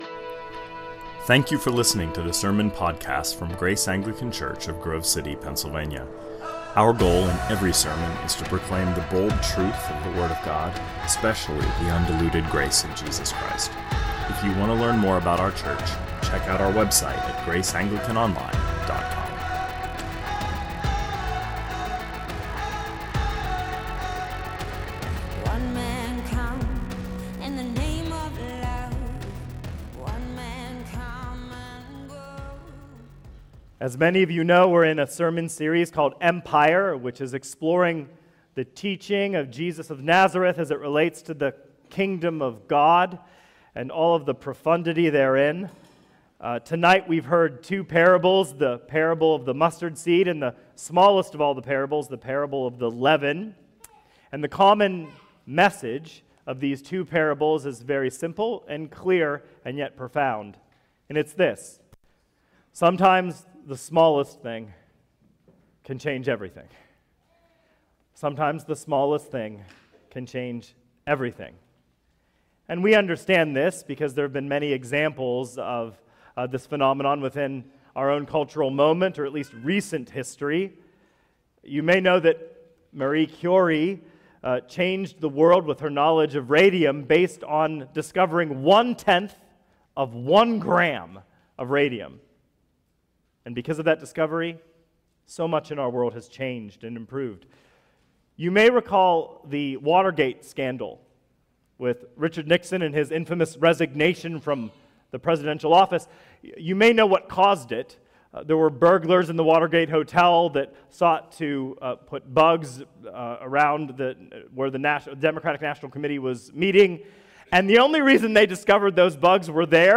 2024 Sermons